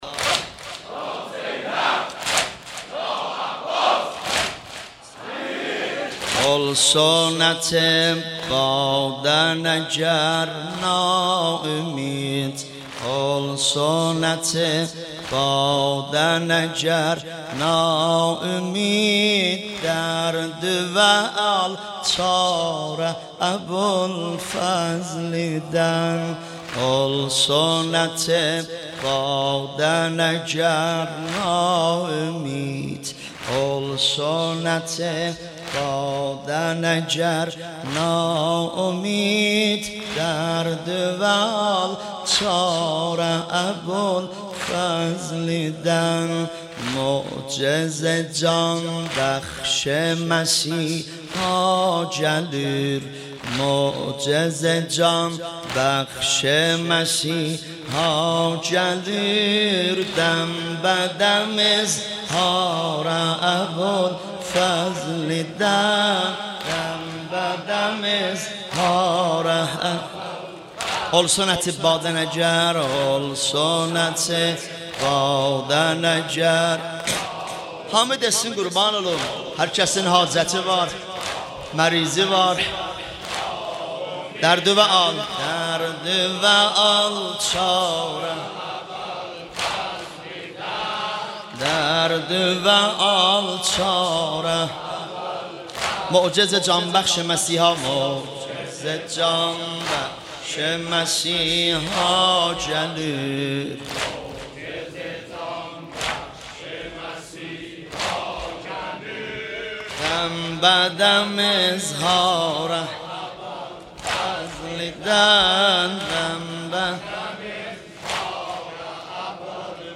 شب تاسوعا مداحی آذری نوحه ترکی